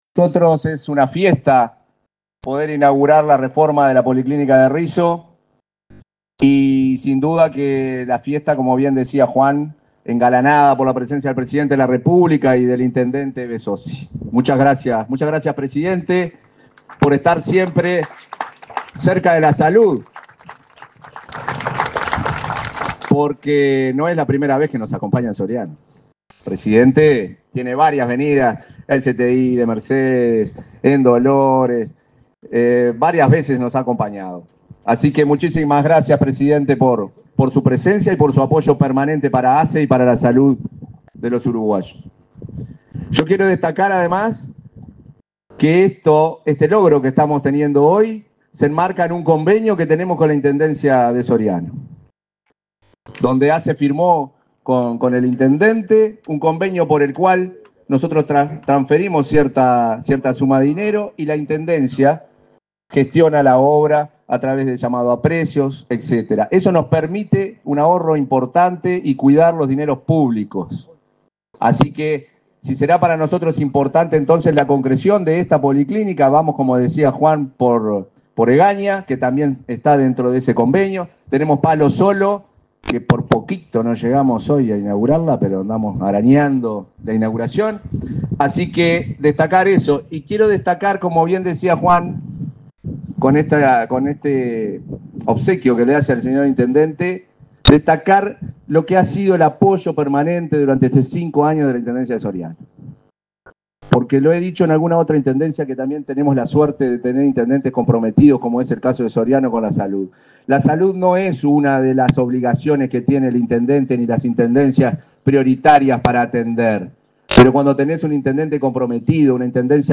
Palabras del presidente de ASSE, Marcelo Sosa
Durante la inauguración de las obras de reforma de la policlínica de Risso, en el departamento de Soriano, este 23 de diciembre, se expresó el